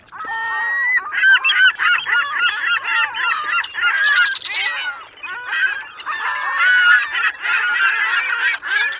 Each sound Fx file has been digitally remastered from their original sources for optimum sound level and reproduction on the PFx Brick.
Animals
Seagulls 1
Seagulls1.wav